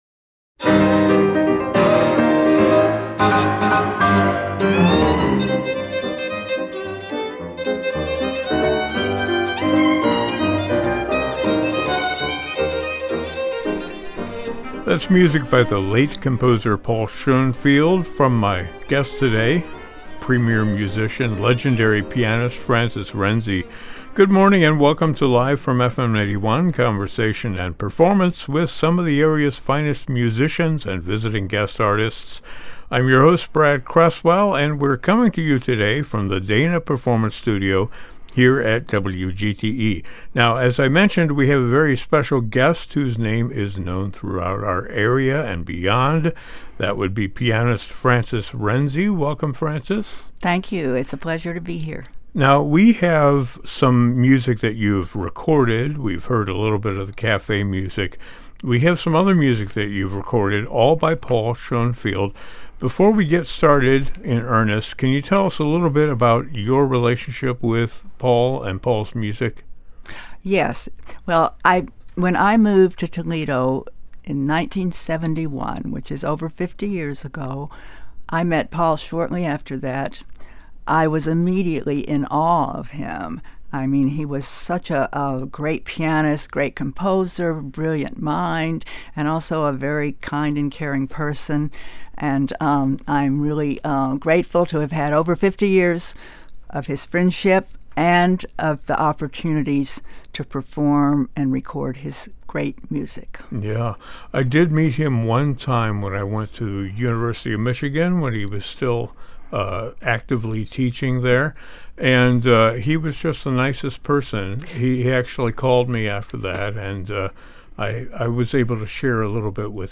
plays in studio and on recording